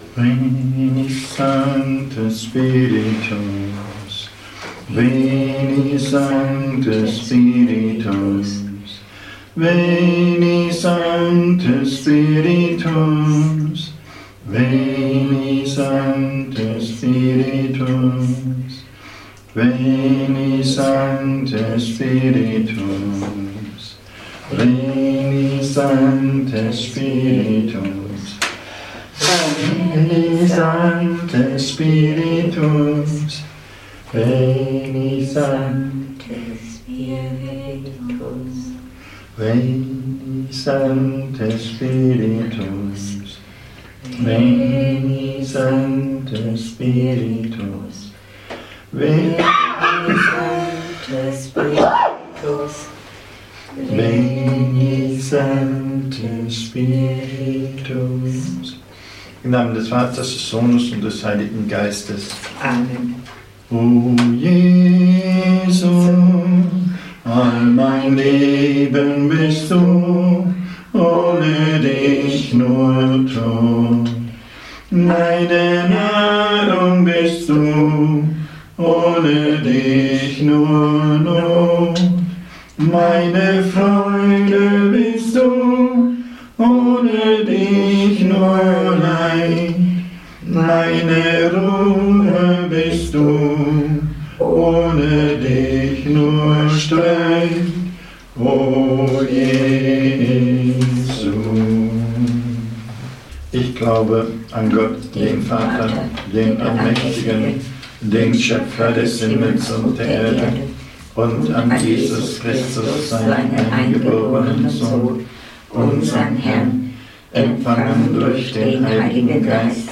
Rosenkranzandachten